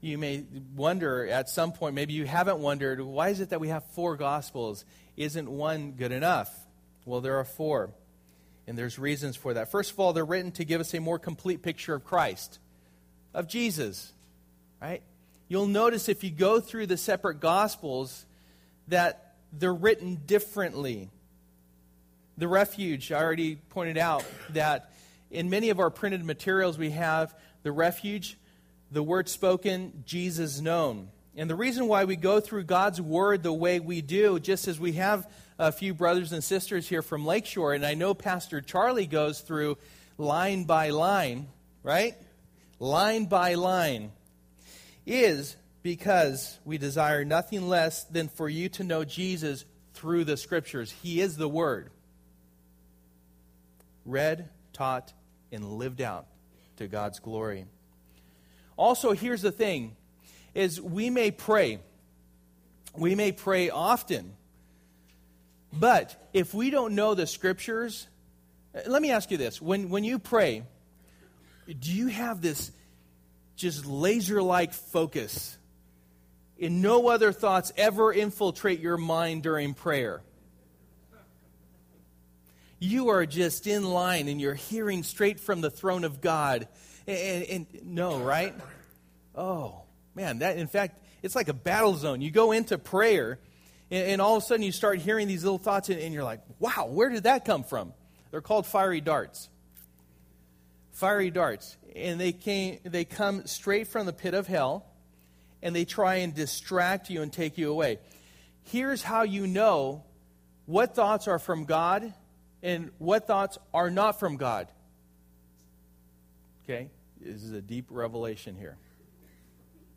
Passage: John 1:1-5 Service: Sunday Morning